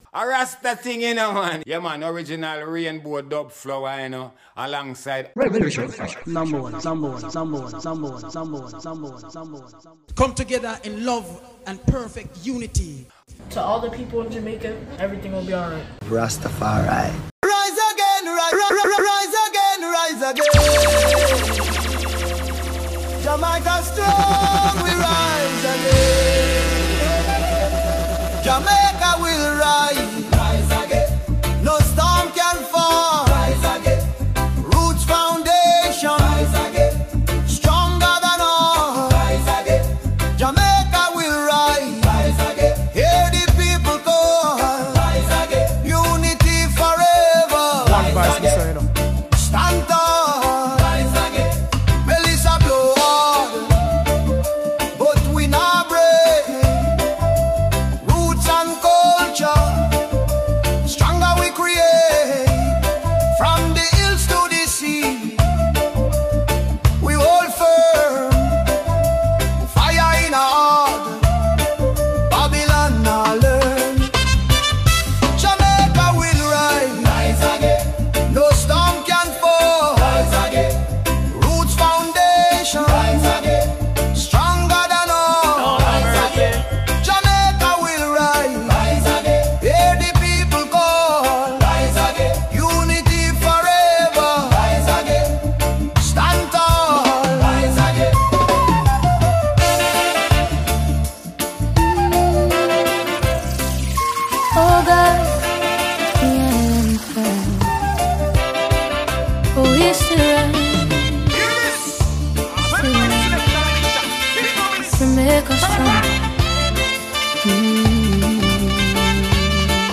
Reggae Music